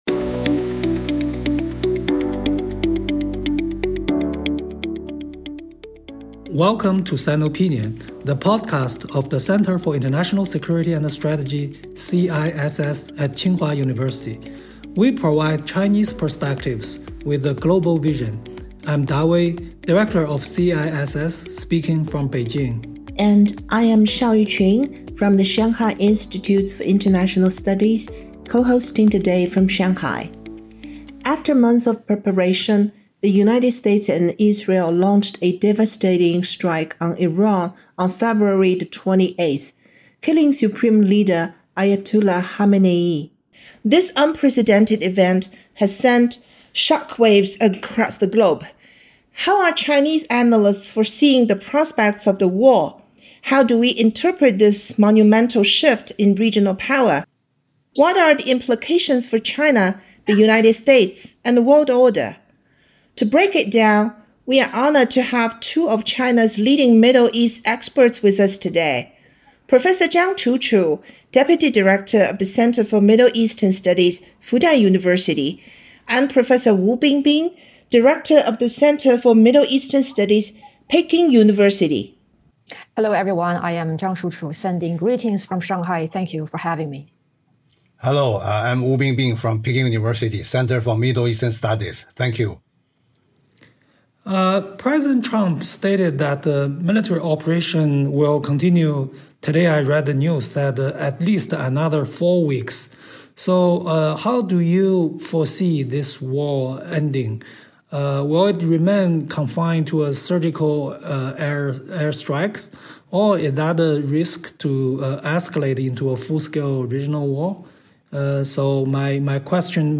四位学者就美国军事行动的战略目标，中东地区秩序的重构，中国的立场与角色，及中美关系的潜在变局等关键问题，展开深度剖析与交流。